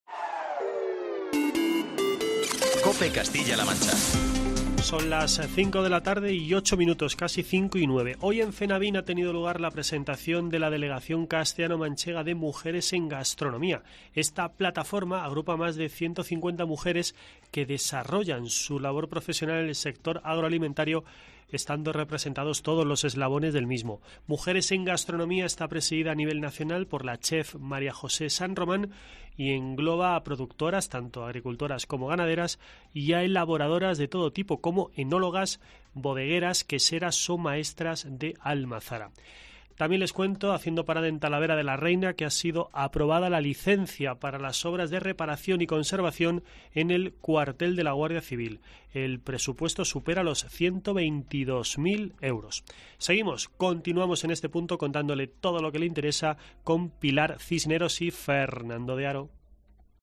boletín informativo de COPE Castilla-La Mancha